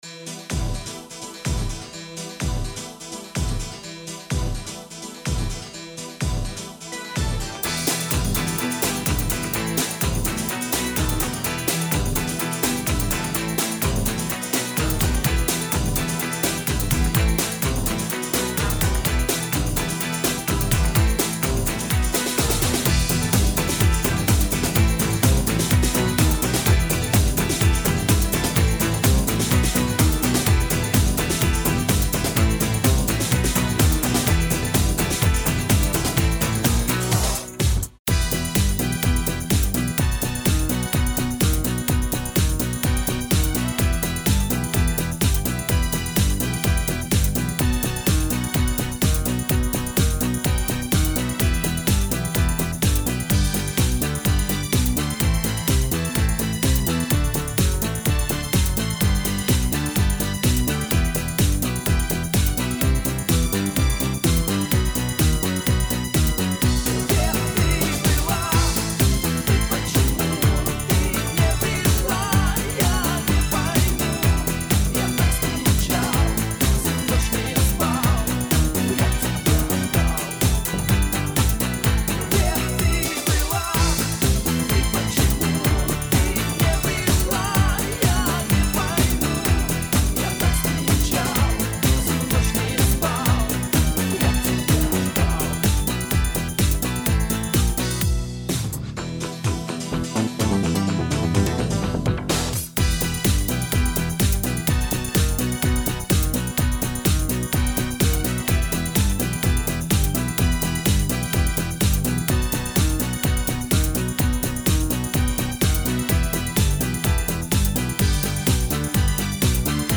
минусовка версия 217174